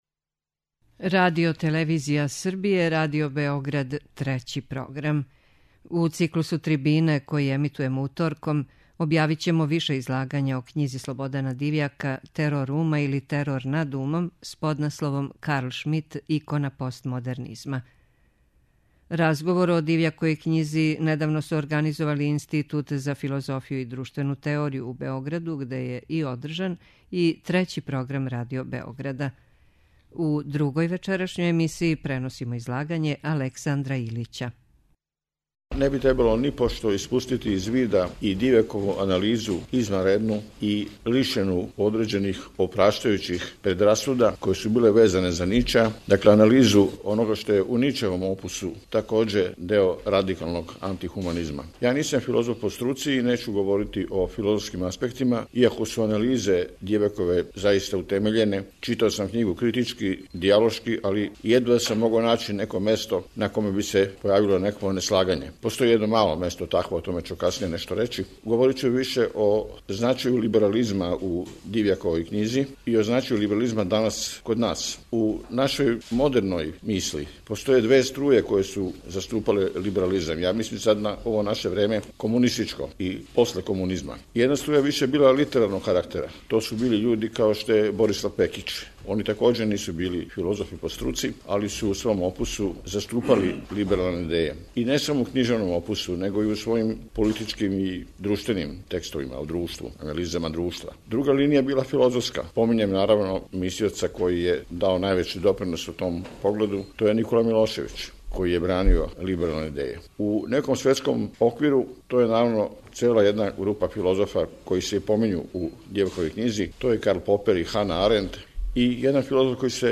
Трибине